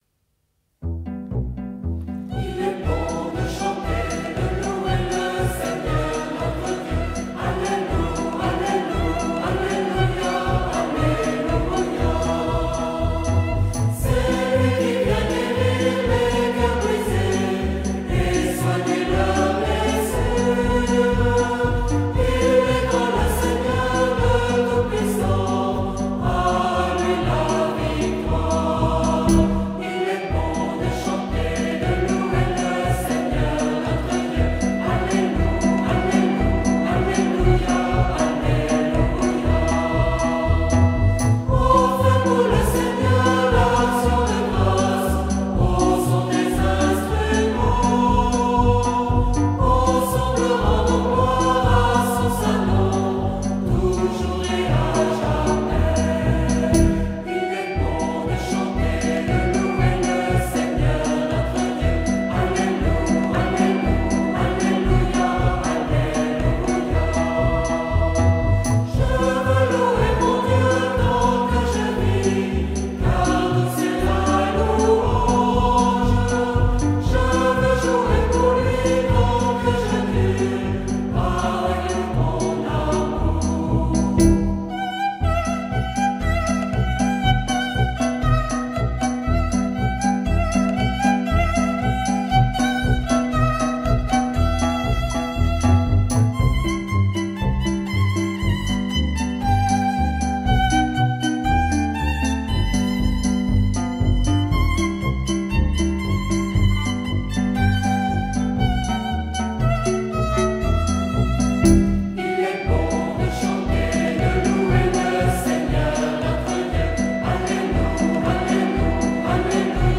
Chants de veillée et de louange